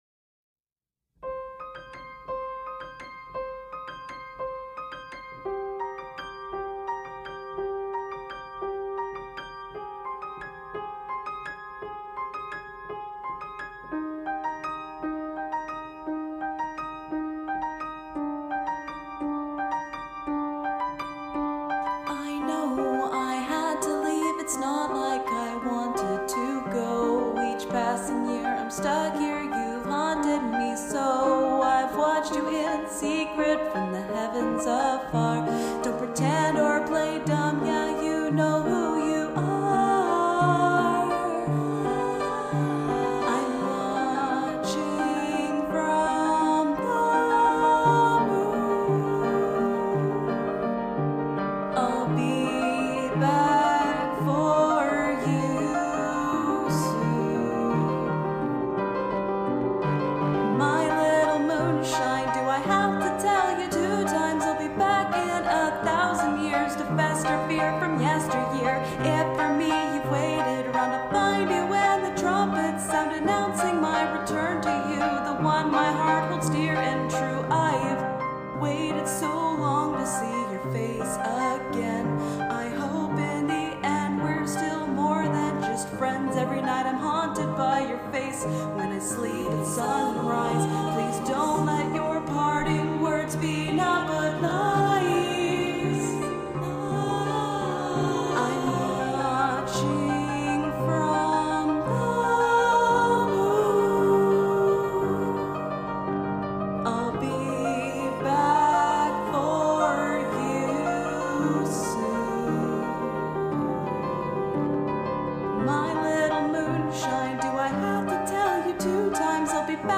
All Lyrics, vocals, and instrumentals done by myself.